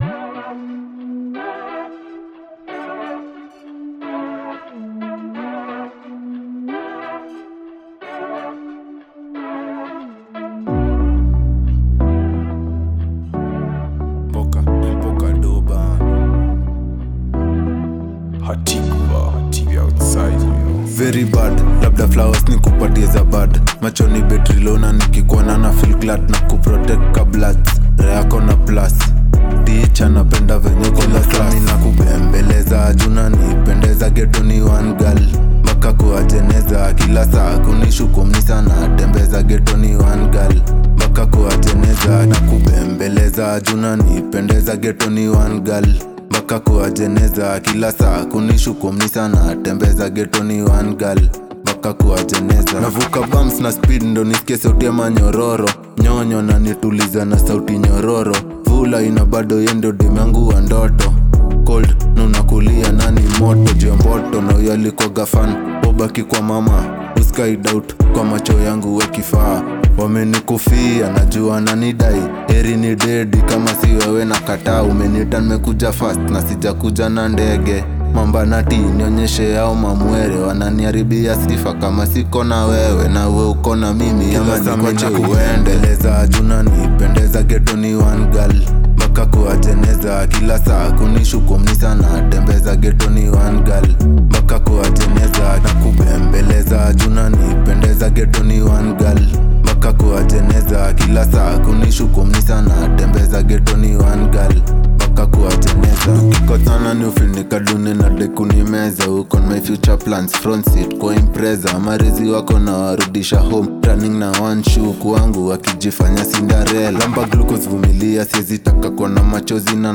With its touching message and soulful vibe